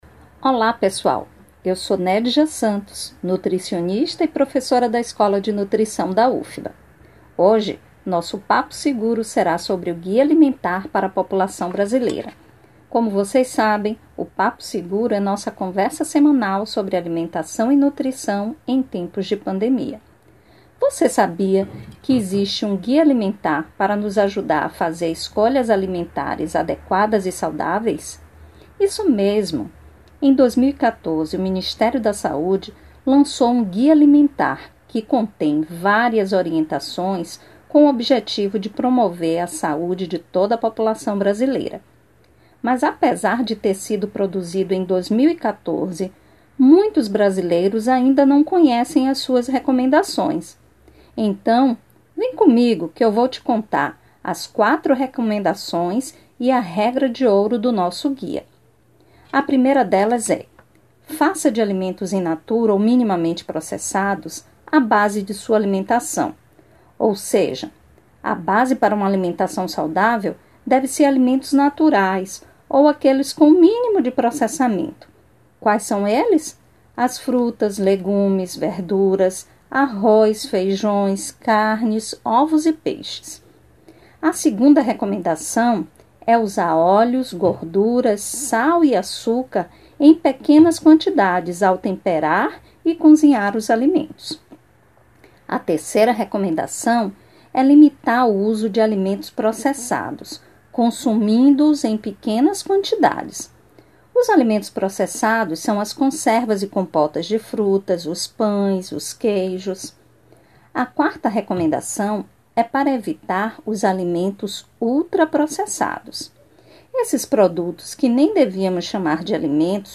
Numa parceria com a ENUFBa- Escola de Nutrição da Universidade Federal da Bahia e Programa Excelsior Saúde, exibido pela Rádio Excelsior da Bahia AM840,  apresentamos mais uma edição da série: Papo Seguro, diálogos sobre alimentação e Nutrição em tempos de pandemia,  como forma de fornecer informações seguras e orientações nutricionais à comunidade durante a pandemia do Novo Coronavírus.